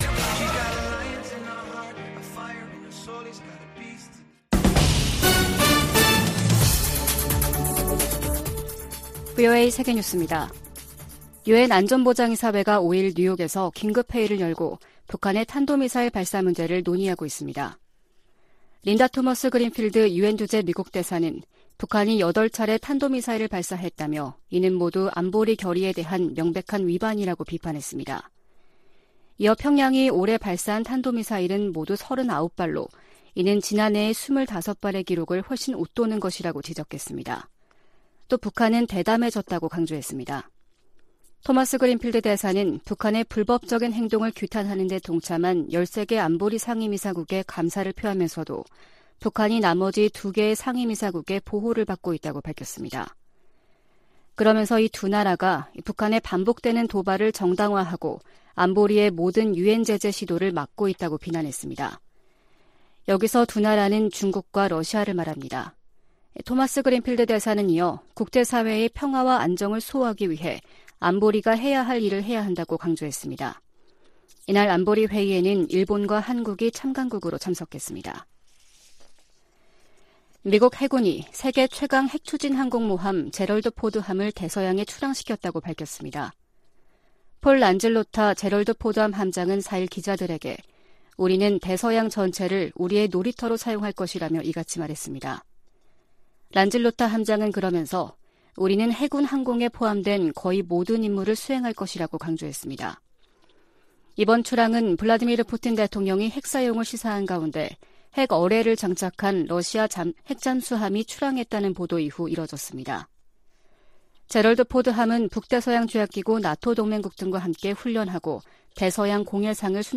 VOA 한국어 아침 뉴스 프로그램 '워싱턴 뉴스 광장' 2022년 10월 6일 방송입니다. 조 바이든 미국 대통령이 윤석열 한국 대통령에게 친서를 보내 동맹 강화를 강조했습니다. 백악관은 일본 열도를 넘어간 북한의 탄도미사일을 ‘장거리’로 지칭하며 무모한 결정을 강력히 규탄한다고 밝혔습니다. 북한이 탄도미사일을 발사하자 미국과 일본 정상이 전화통화를 하고 긴밀한 협력 의지를 재확인했습니다.